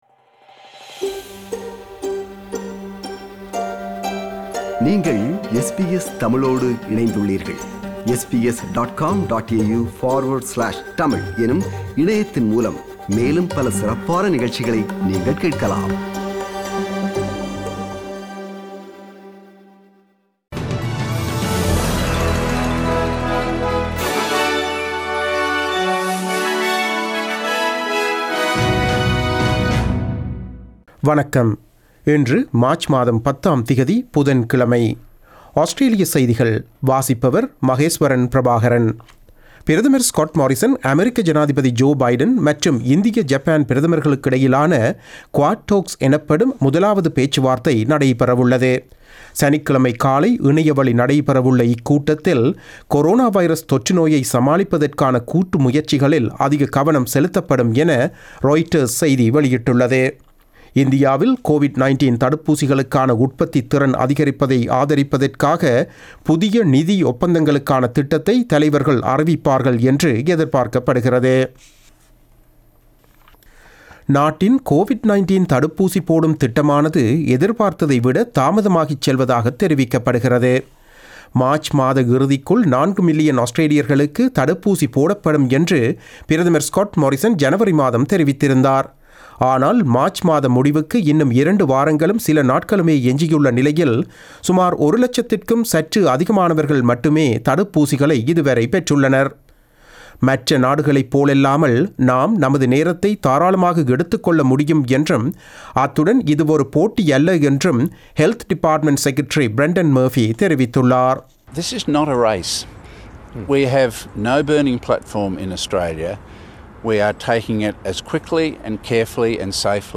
Australian news bulletin for Wednesday 10 March 2021.